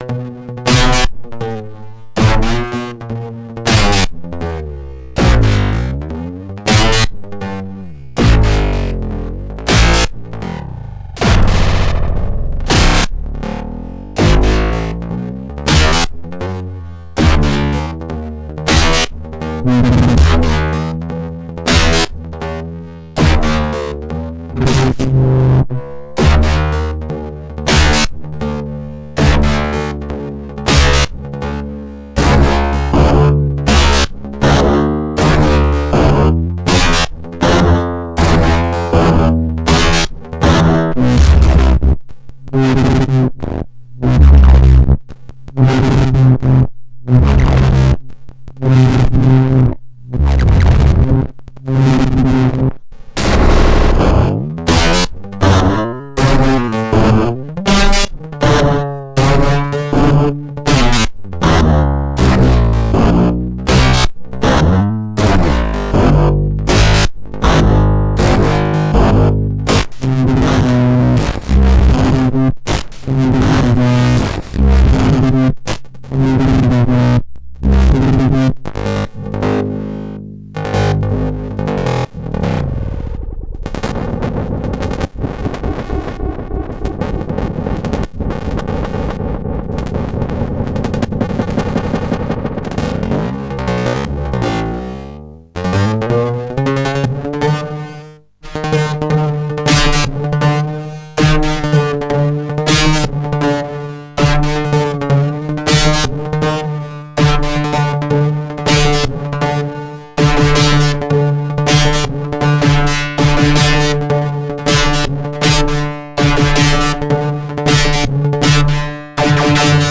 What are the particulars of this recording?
LXR-02 into Alesis 3630.